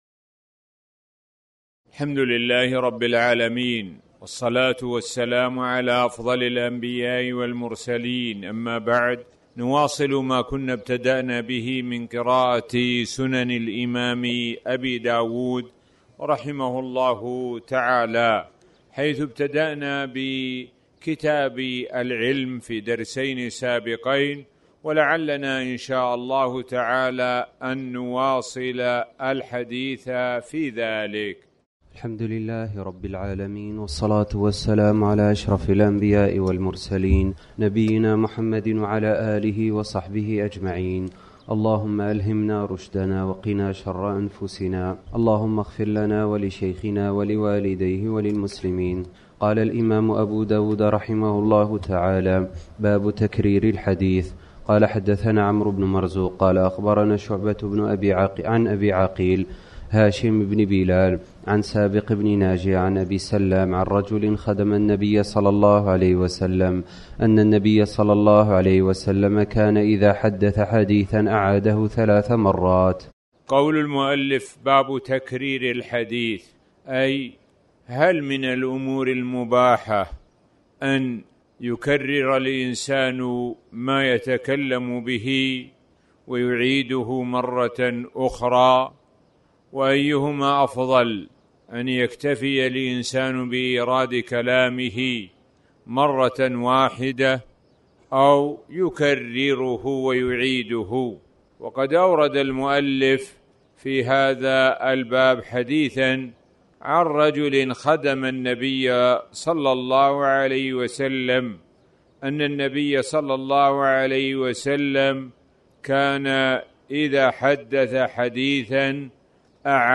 تاريخ النشر ١٢ رمضان ١٤٤٠ هـ المكان: المسجد الحرام الشيخ: معالي الشيخ د. سعد بن ناصر الشثري معالي الشيخ د. سعد بن ناصر الشثري كتاب العلم